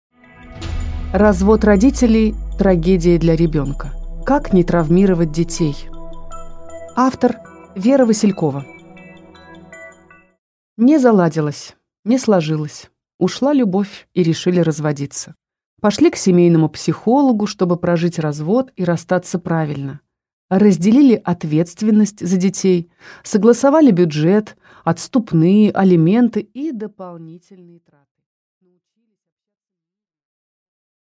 Аудиокнига Развод родителей – трагедия для ребенка | Библиотека аудиокниг
Прослушать и бесплатно скачать фрагмент аудиокниги